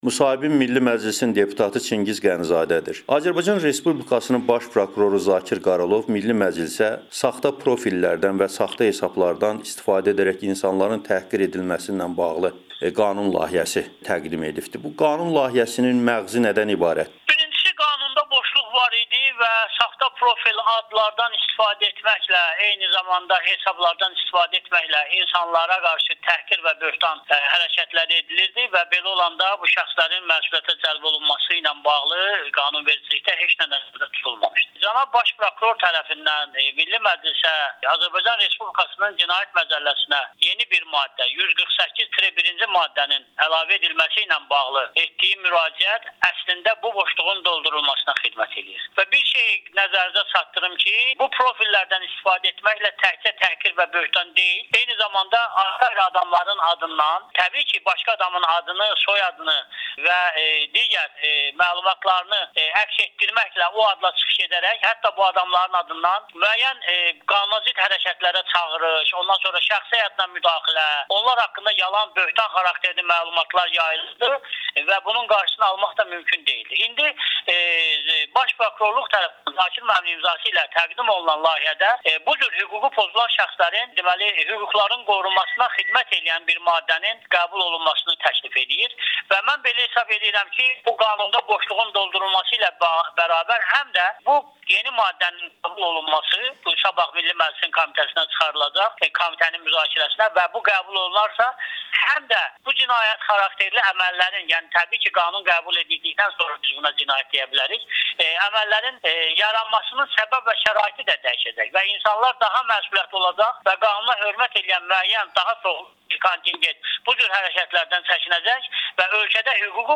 Milli Məclisin deputatı Çingiz Qənizadənin Amerikanaın Səsinə müsahibəsi